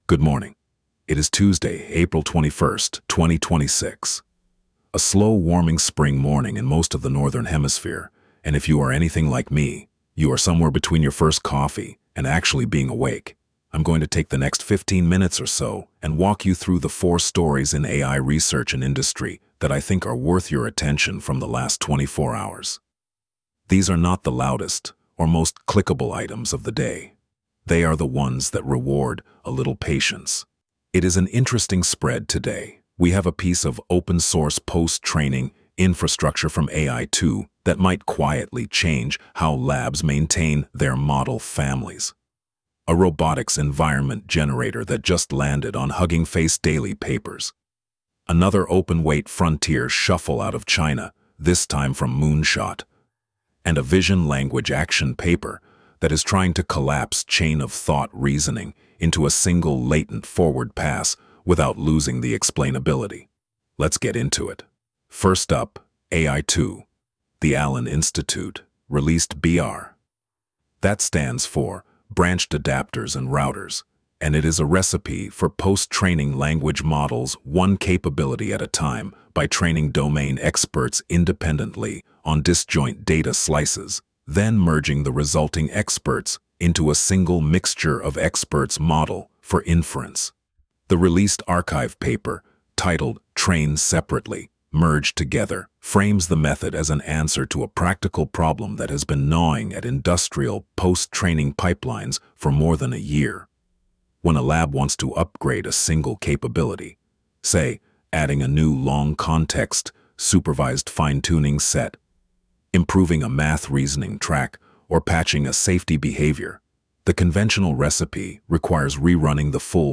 Wolf Digest — 2026-04-21 Coverage window: 2026-04-20 10:45 ET → 2026-04-21 03:02 ET ▶ Press play to listen Tuesday, April 21, 2026 11m 47s · top-4 narrated briefing Subscribe Apple Podcasts Spotify Download MP3